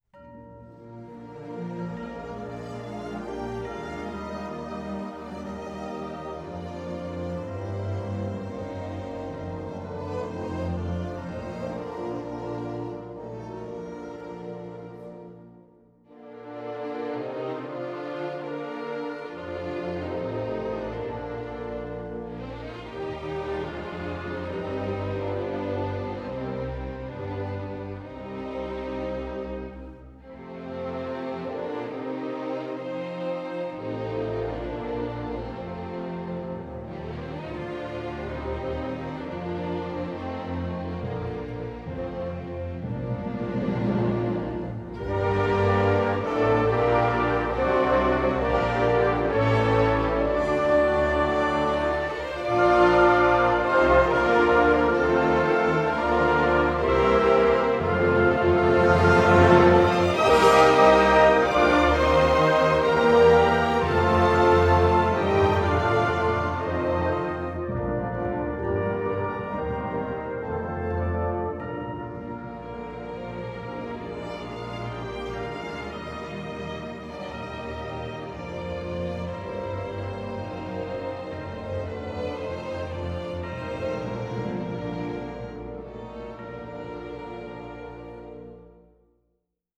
A MÁV Szimfonikus Zenekar a MOB megkeresésére még 2013 nyarán, a Nemzetközi Olimpiai Bizottság ide vonatkozó szabályainak megfelelően készítette el a Magyar Himnusznak azt a 90 másodperces zenekari változatát, melyet az olimpiai játékokon és az olimpiai eseményeken fognak játszani.
A nemzetközi standard elvárásainak megfelelően ez egy énekszólam nélküli zenekari előadás másfél percben, a közösségi éneklésre is alkalmas B-dúr hangnemben. Különlegessége továbbá a zeneszerző által a kotta külön sorában jelzett, ám korábbi hangzó anyagokban még sosem hallott „Harangs(z)ó”; ez egyértelmű utalás az idén éppen 560 esztendeje történt fényes nándorfehérvári diadalra, amellyel egyidejűleg III.